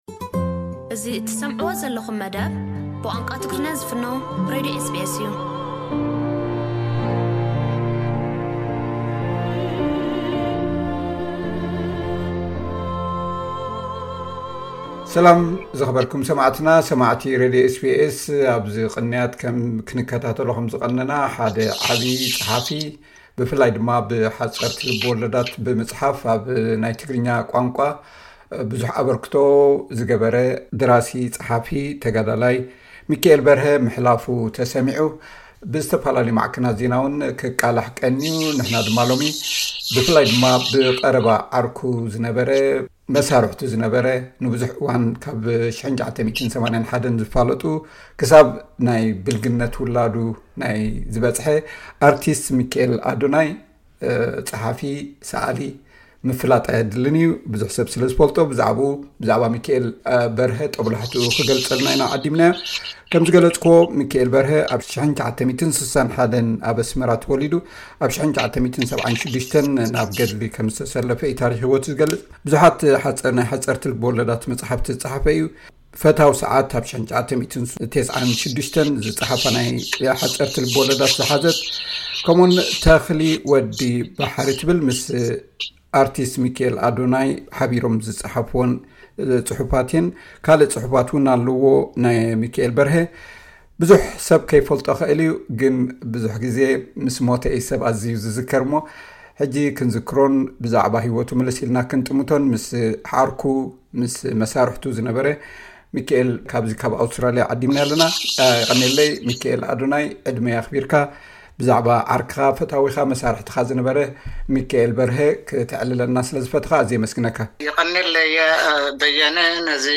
ዝርርብ